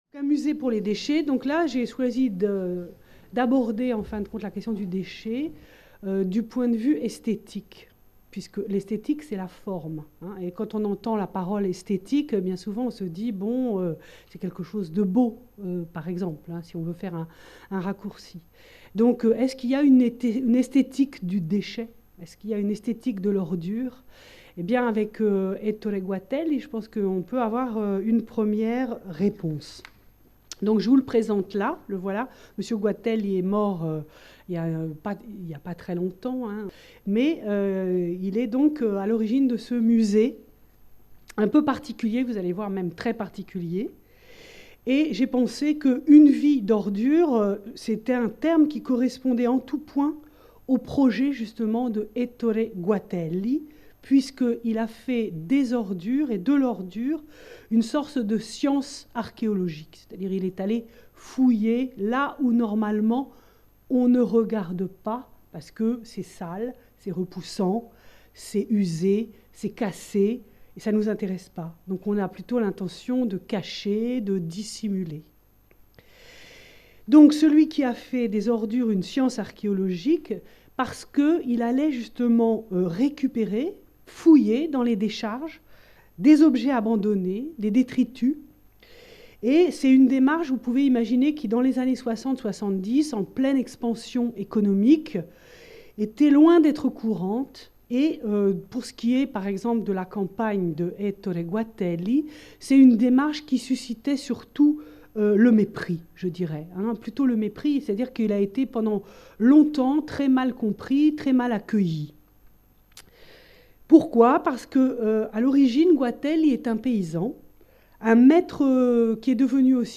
Cette communication a été filmée lors de la série d'événements organisé par la MRSH autour de la question des déchets, abordant les enjeux écologiques qui se posent à nos sociétés.